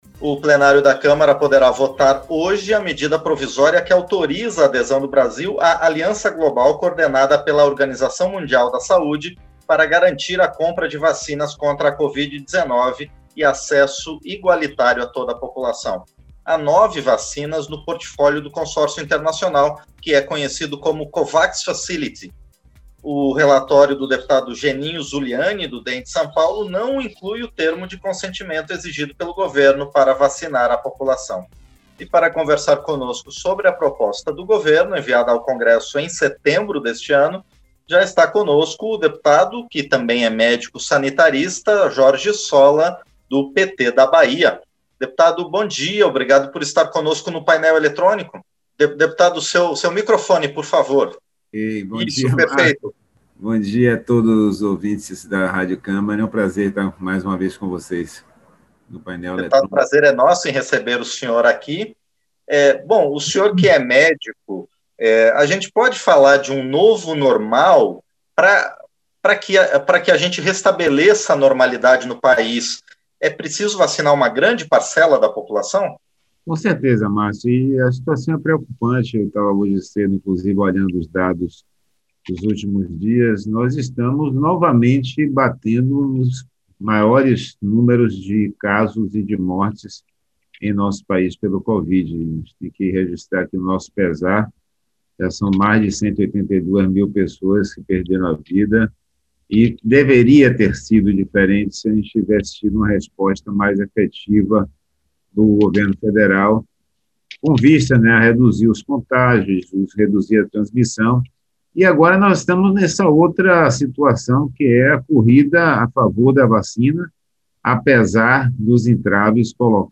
Entrevista - Dep. Jorge Solla (PT-BA)